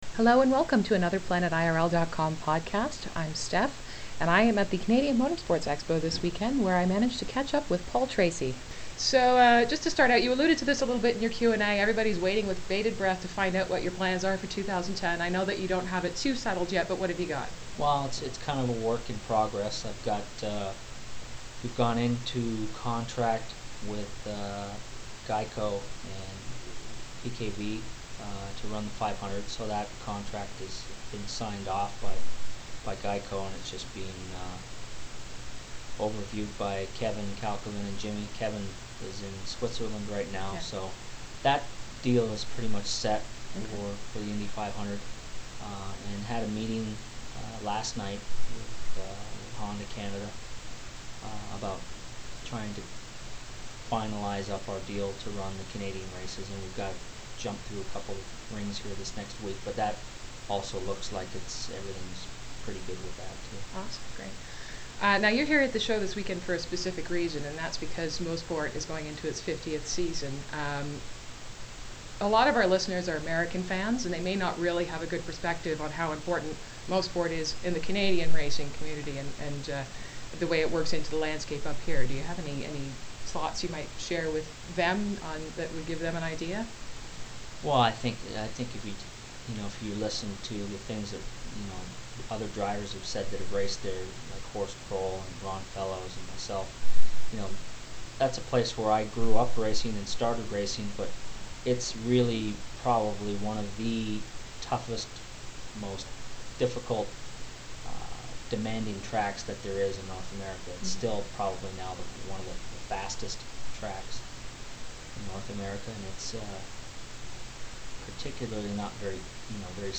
An interview with Paul Tracy
This weekend at the Canadian Motorsports Expo, I had the opportunity to catch up with PT. We chatted about the status of his 2010 plans, a couple of news items that have come up since our last interview with him, and the TV show he’s got in the works.